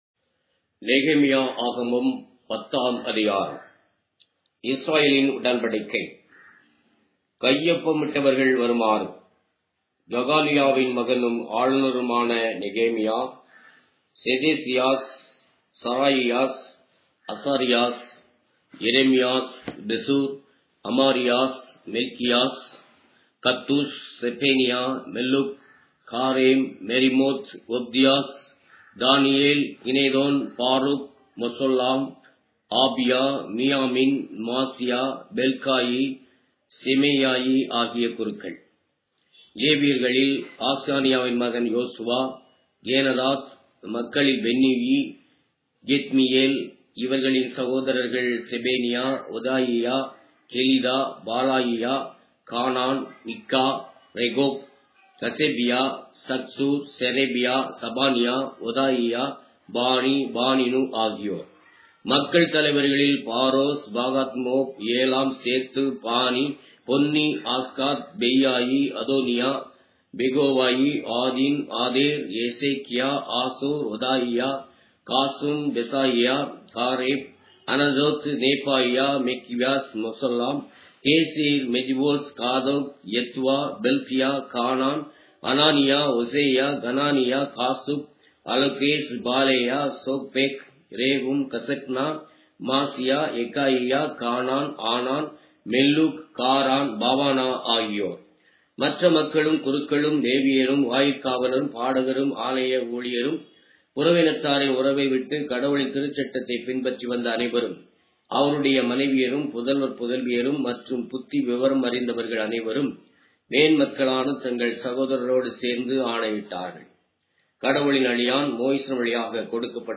Tamil Audio Bible - Nehemiah 8 in Rcta bible version